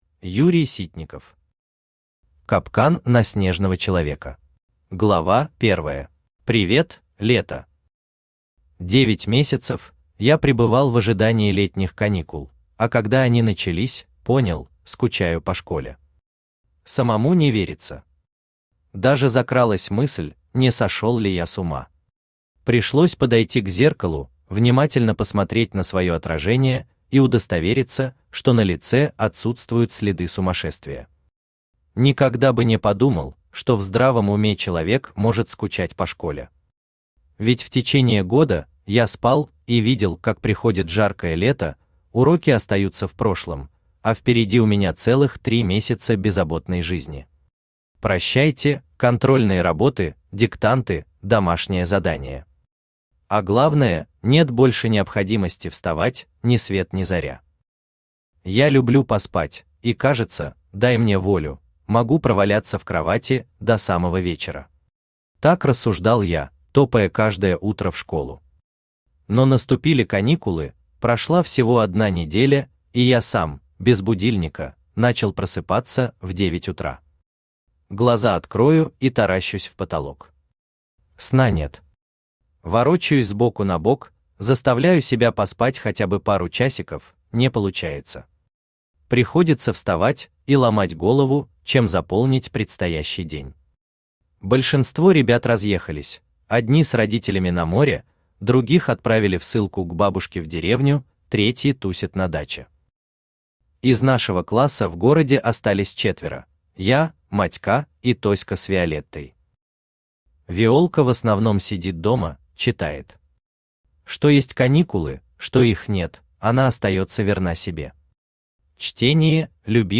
Аудиокнига Капкан на снежного человека | Библиотека аудиокниг
Aудиокнига Капкан на снежного человека Автор Юрий Вячеславович Ситников Читает аудиокнигу Авточтец ЛитРес.